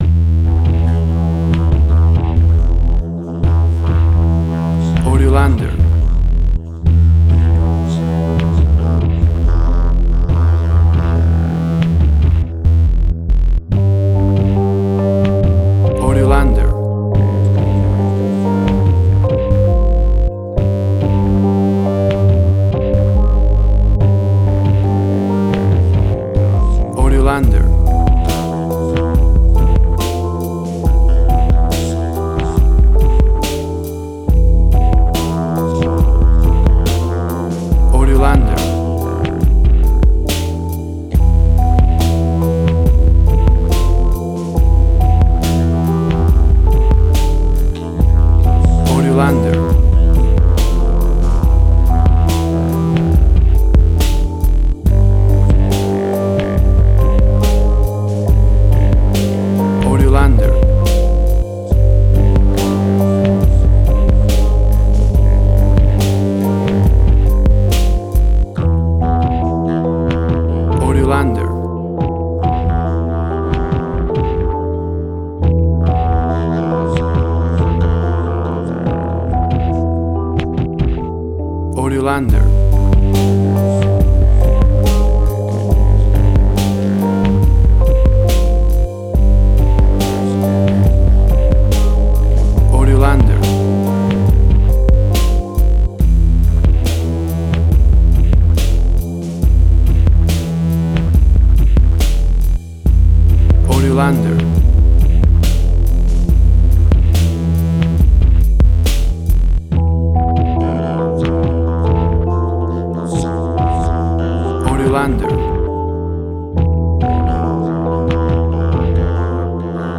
Strange and wird robot trip hop music
Tempo (BPM): 70